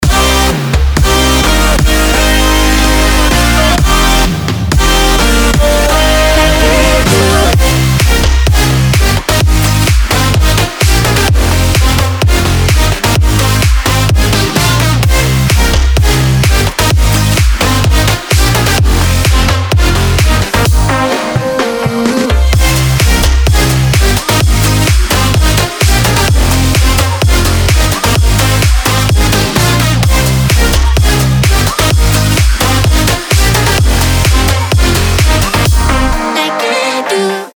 • Качество: 320, Stereo
громкие
dance
Electronic
EDM
без слов
future house
club
энергичные